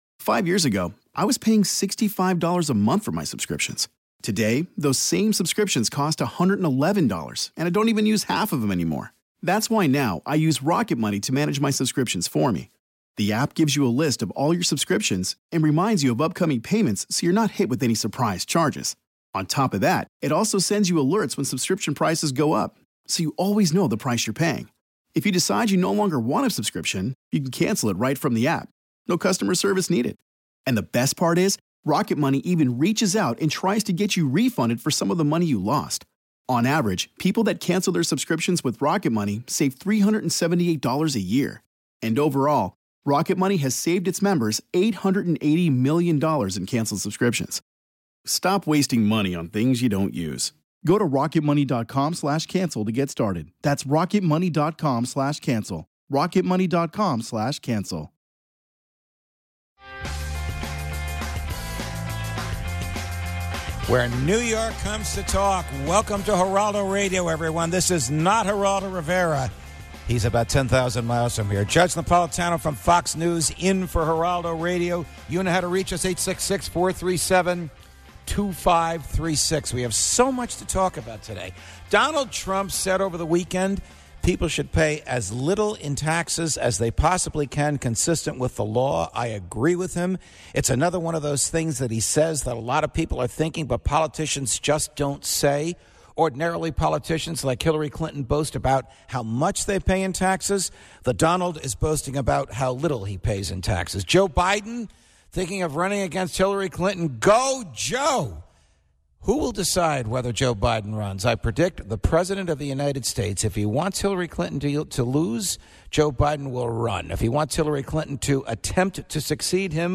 Judge Napolitano sits in for Geraldo, discussing Donald Trump, NY Sports and much more